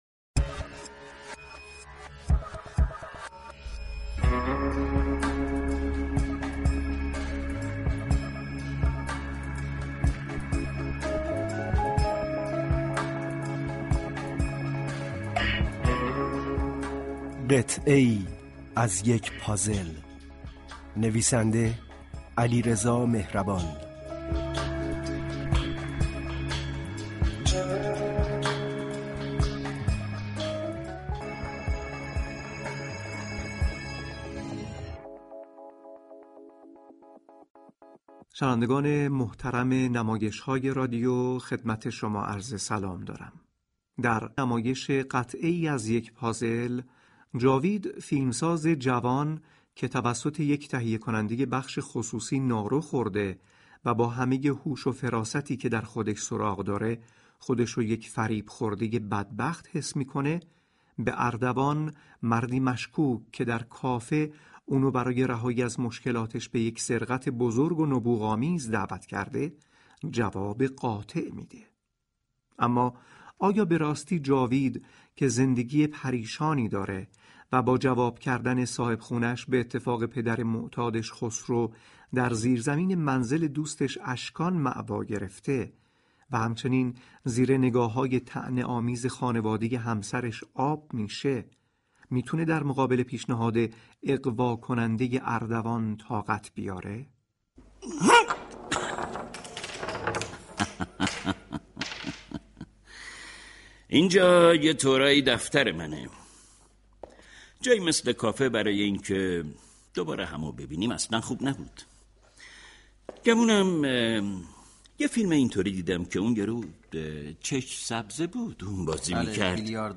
به گزارش روابط عمومی اداره كل هنرهای نمایشی رادیو ، این نمایش رادیویی را علیرضا مهربان نوشته و ماجرای زندگی فیلمسازی را روایت می كند كه با سختی های زیادی روبه روست .